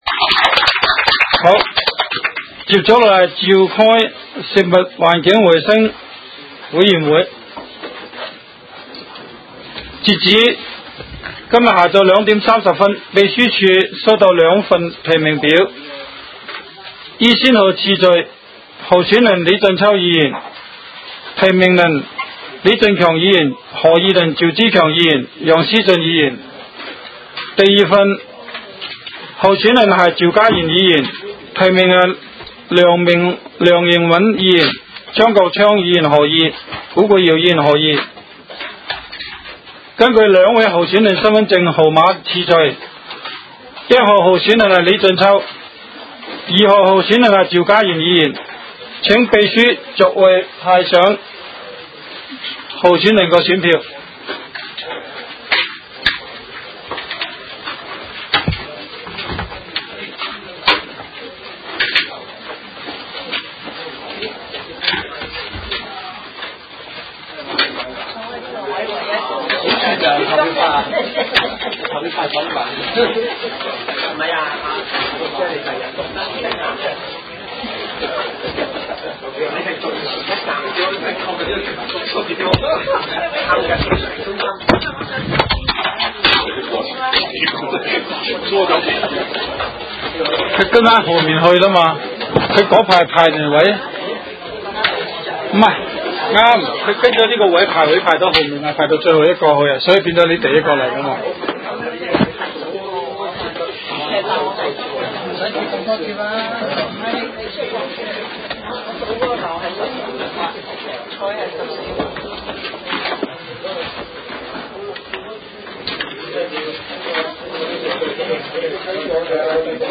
委员会会议的录音记录
东区区议会会议室